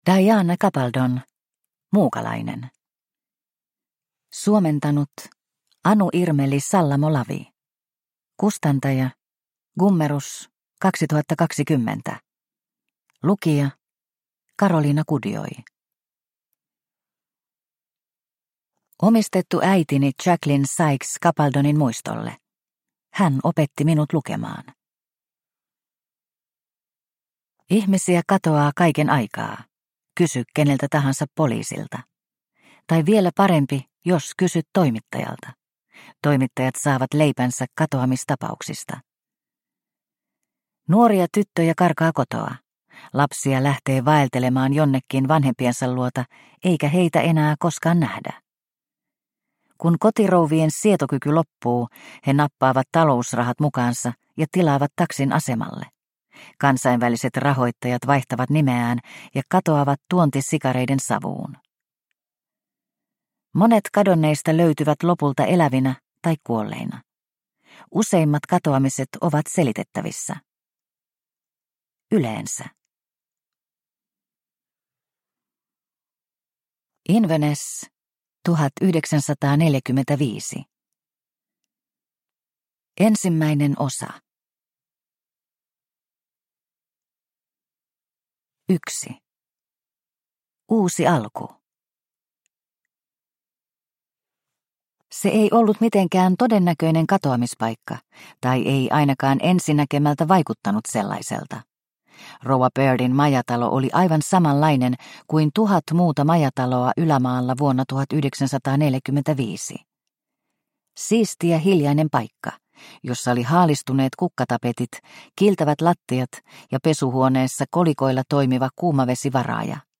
Muukalainen – Ljudbok – Laddas ner